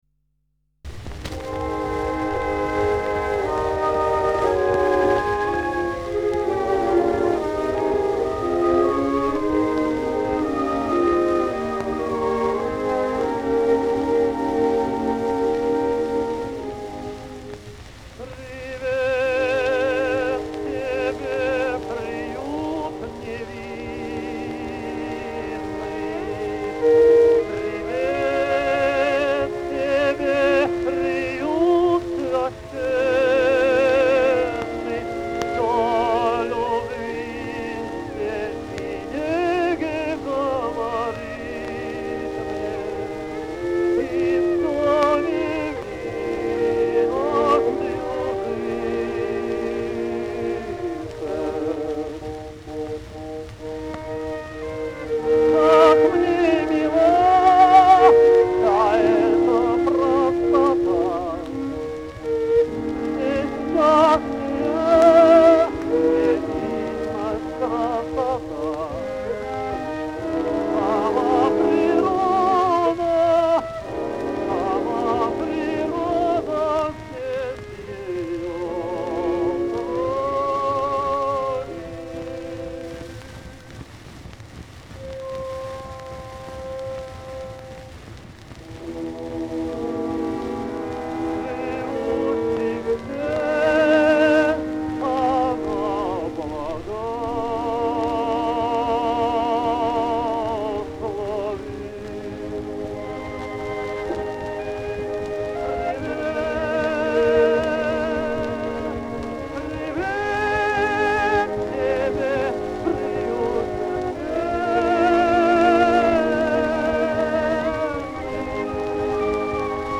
Андре́й Ма́ркович Лаби́нский (20 августа 1871, Харьков, Российская империя — 8 августа 1941, Москва, СССР) — русский и советский оперный и камерный певец, лирико-драматический тенор.
Обладал ровным, гибким голосом приятного мягкого тембра и широкого диапазона (брал ноту фа третьей октавы).
17 - Андрей Лабинский - Каватина Фауста (Ш.Гуно. Фауст) (1905)